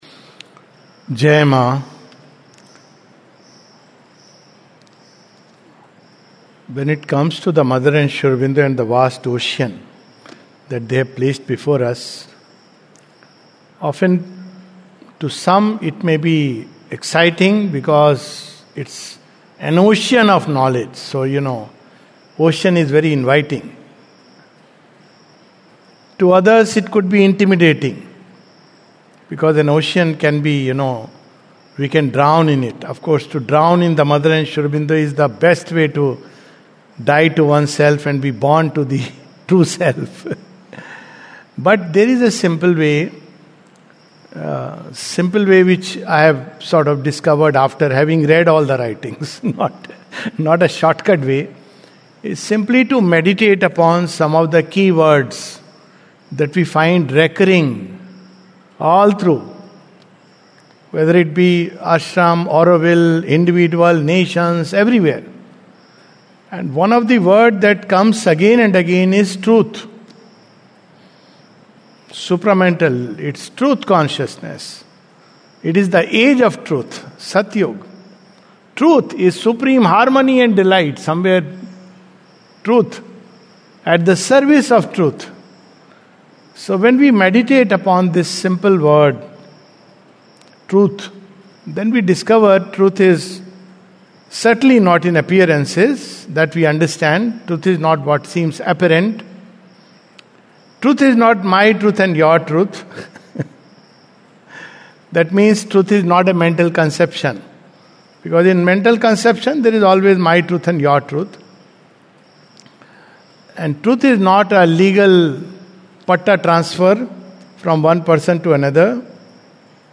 This talk delivered at the Savitri Bhavan on the occasion of the inauguration of the Mother's writings on Auroville.